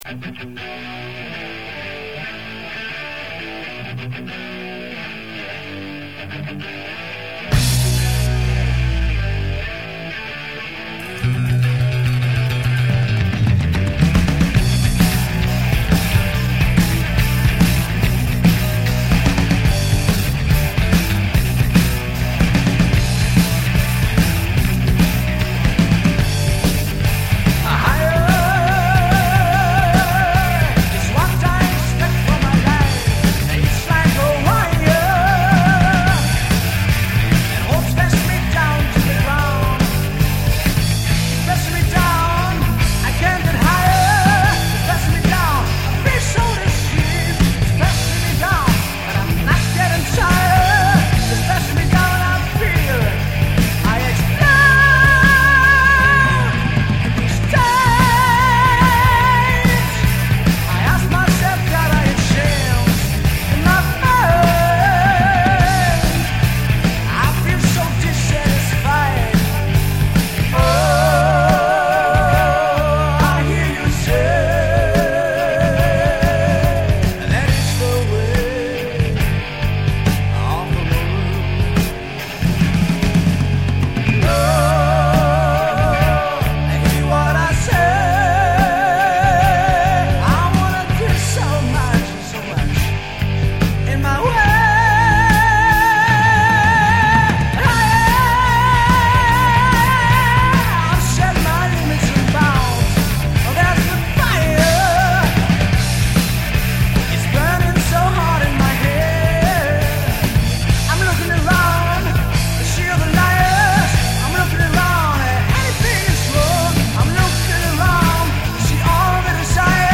Stil : Progressive Metal